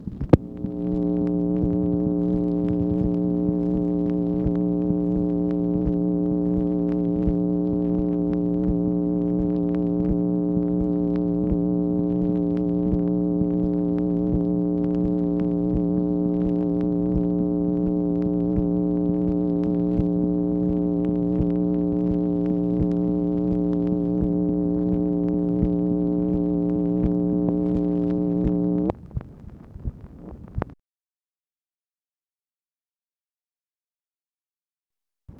MACHINE NOISE, September 1, 1965